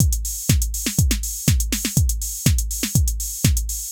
AIR Beat - Mix 2.wav